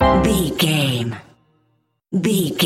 Aeolian/Minor
flute
oboe
strings
circus
goofy
comical
cheerful
perky
Light hearted
quirky